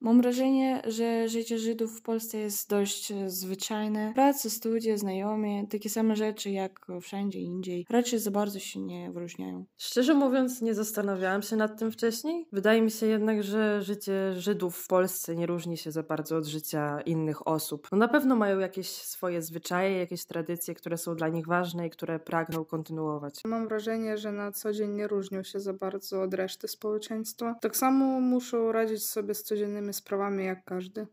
Wywiad-zydzi.mp3